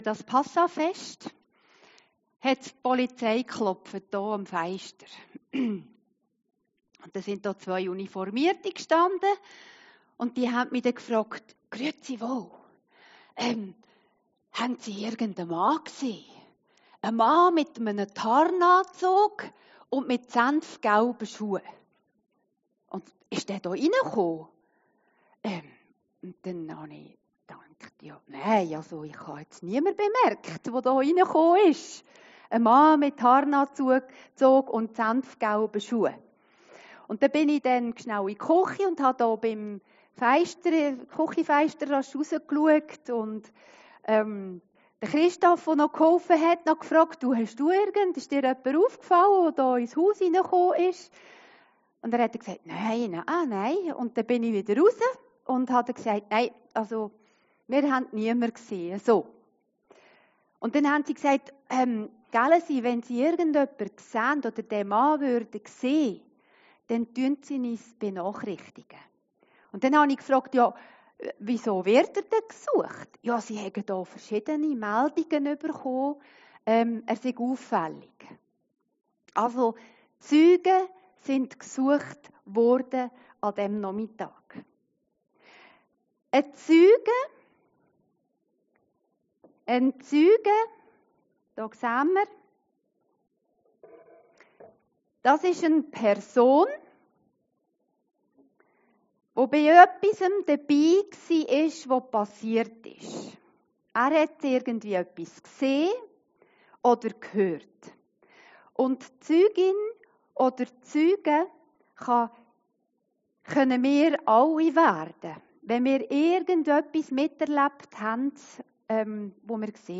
Predigten Heilsarmee Aargau Süd – Zeugen